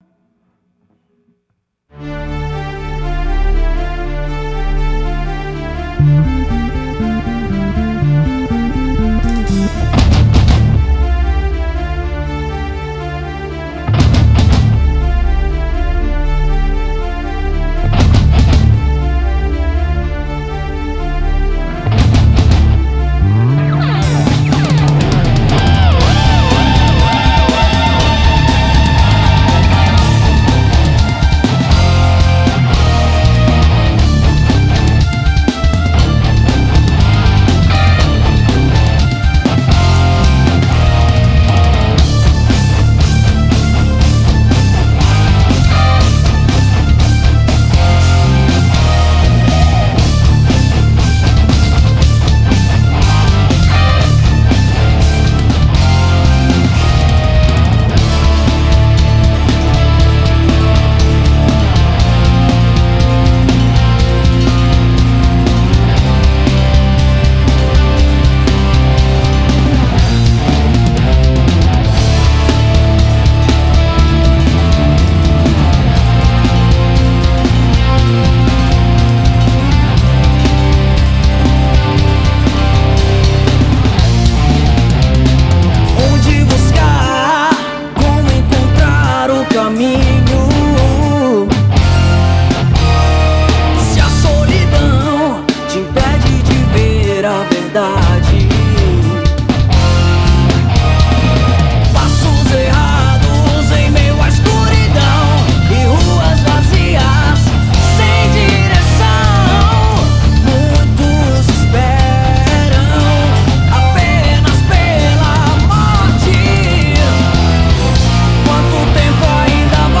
mesclando estilos como rock, baladas, rap e adoração